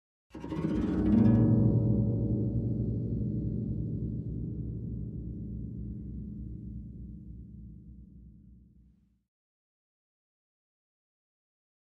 Harp, Low Strings Ascending Gliss, Type 5